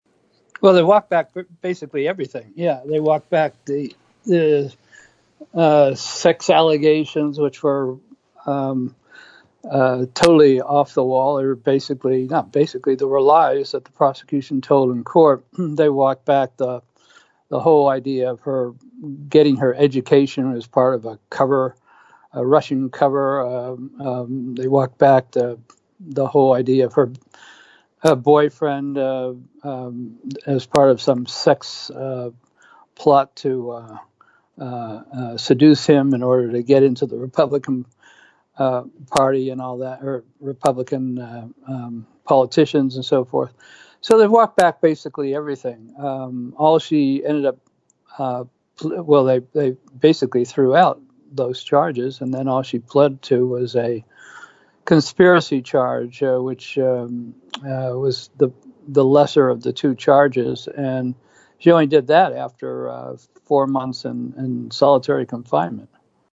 In-Depth Interview: NatSec Journalist James Bamford Says ‘Almost Everything You’ve Heard About Maria Butina is Wrong’